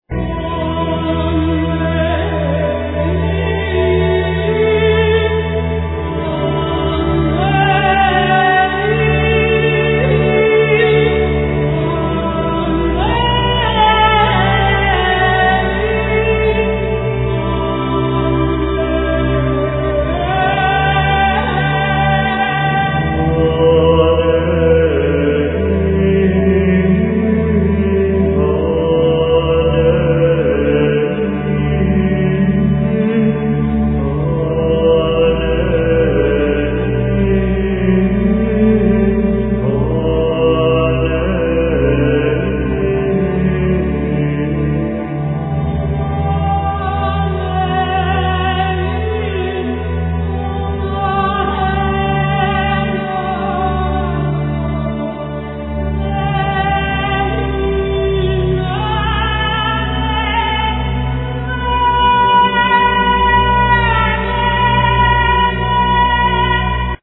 Vocals, Percussions
Programming, Guitars, Saz, Percussions
Programming, Keyboards, Santur, Percussions
Percussions, Tapan, Gaidunitza, Daf
Renaissance lute, Block flute